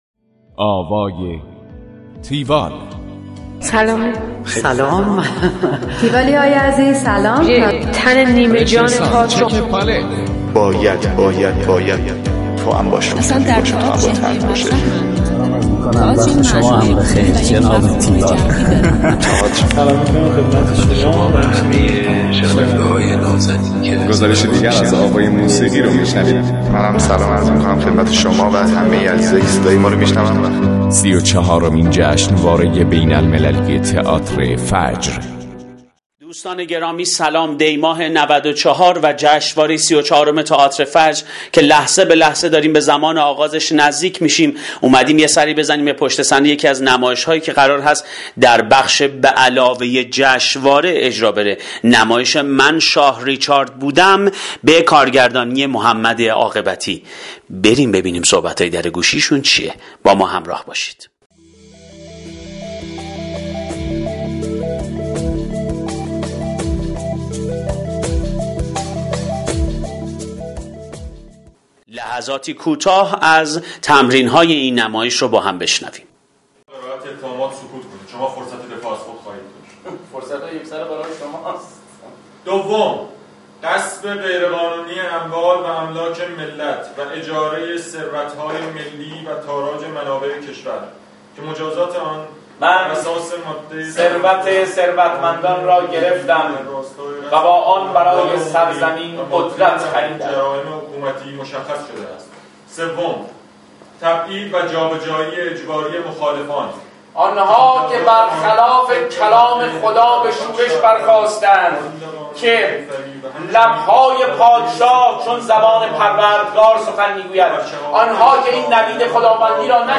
گزارش آوای تیوال از نمایش من شاه ریچارد بودم
گفتگو با
- افشین هاشمی / بازیگر
همراه با بخش هایی از تمرین